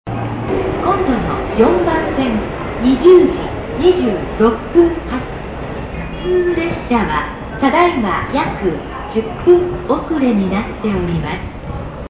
次発放送　遅延10分
遅延時の次発放送です。
５分以上遅れると流れ、東海道線からは言い回しが変わりました。